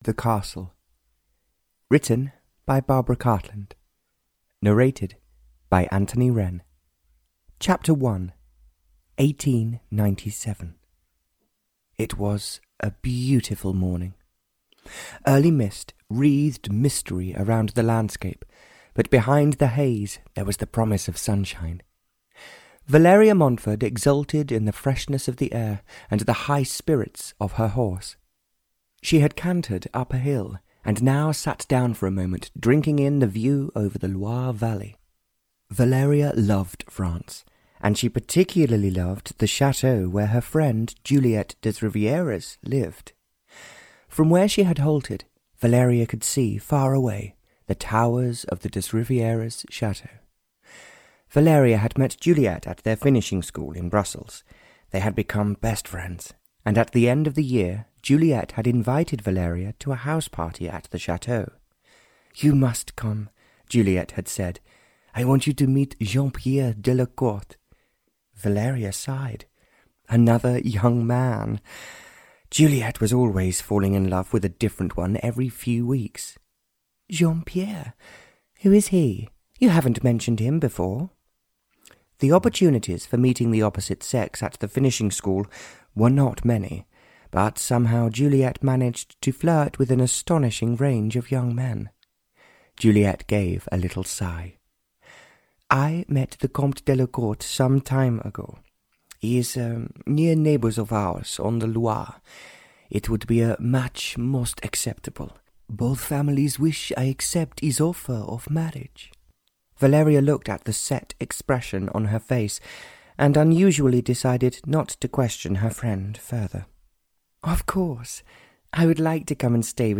Audio knihaThe Castle (Barbara Cartland s Pink Collection 76) (EN)
Ukázka z knihy